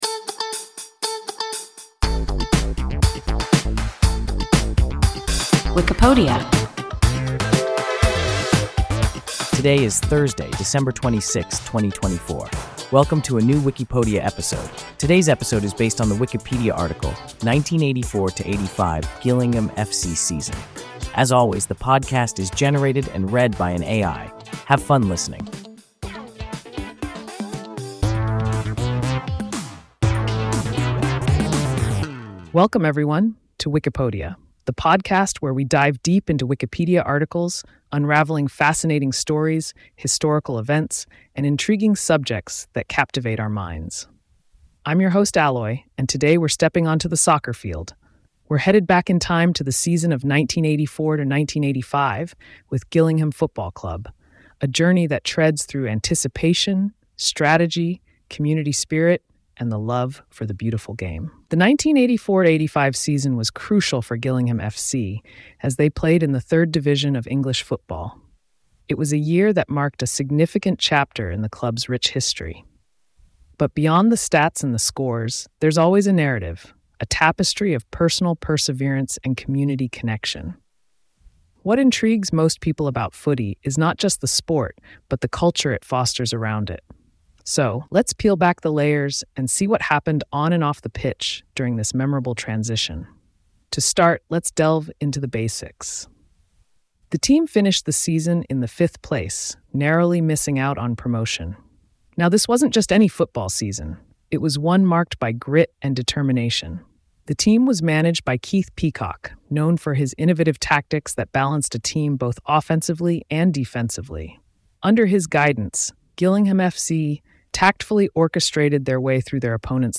1984–85 Gillingham F.C. season – WIKIPODIA – ein KI Podcast